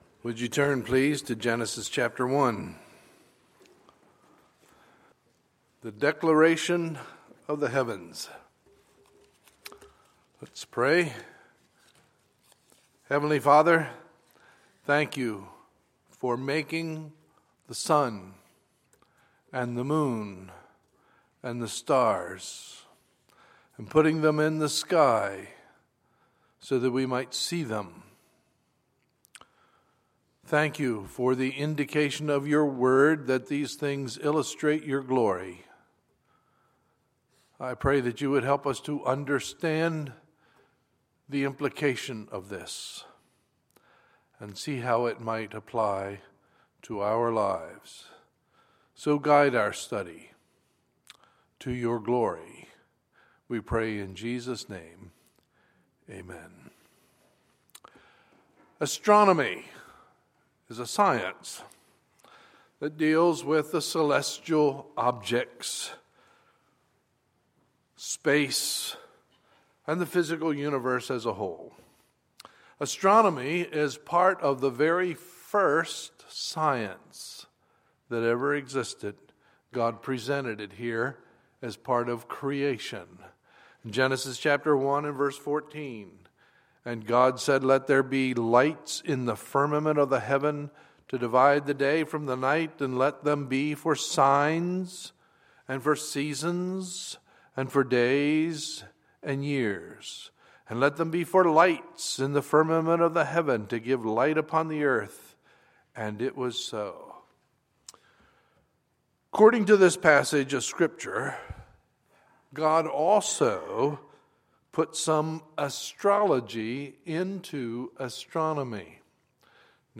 Sunday, February 14, 2016 – Sunday Morning Service